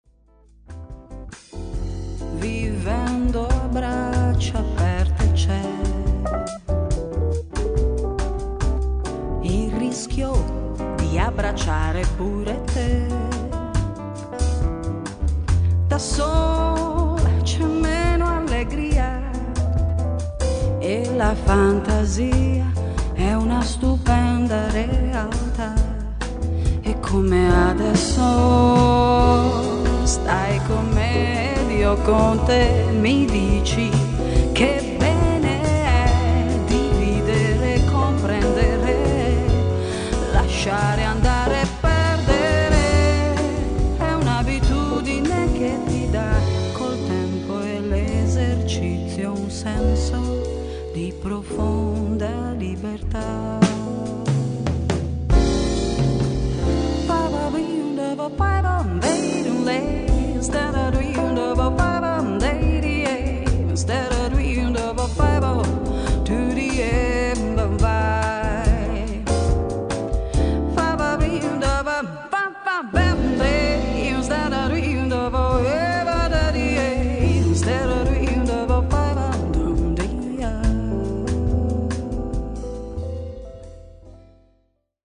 chitarra e voce
pianoforte
contrabbasso
batteria